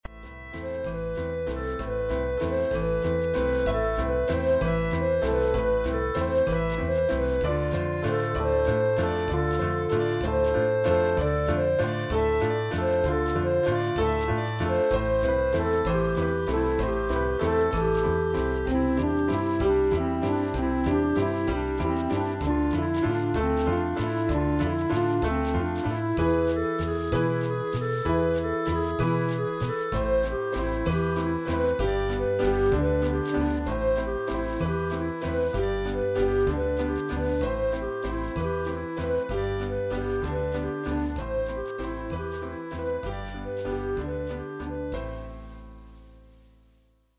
Une valse originale qui nous entraîne autour du monde.